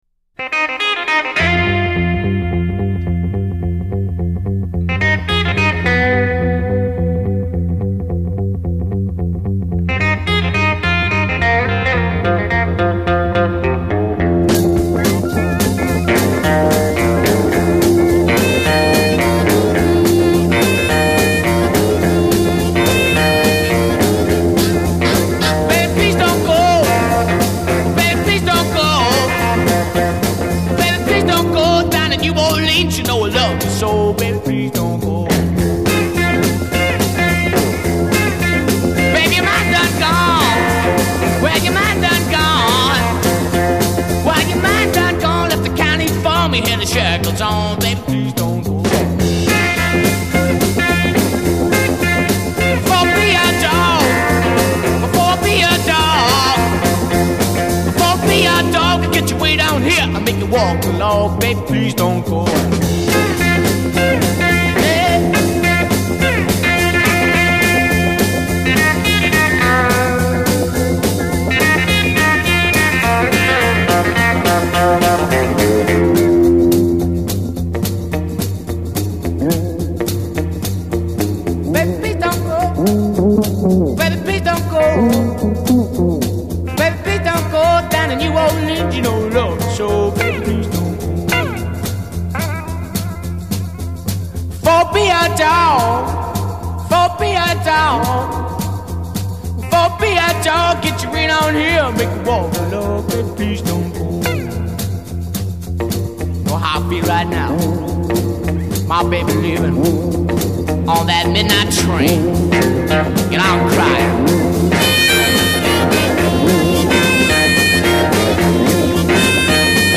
Intro p2 0: 10 add drums, organ, tambourine, & harmonica
drums with cymbal for first half, hi-hat for second half   A
guitar plays intro under voice at end of line   A
fill : build guitar fill, bass slides above organ bass;
outro recap intro melody and end suddenly
British Blues